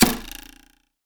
ArrowPenetration_Wood 01.wav